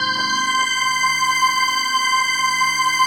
DM PAD3-13.wav